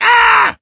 Wario's voice clip when hitting an enemy in Manta Rings.
Wario_(AHHHH!)_-_Mario_Party_4.oga